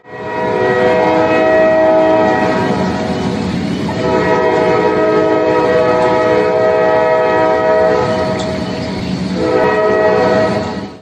train 3
Tags: project personal sound effects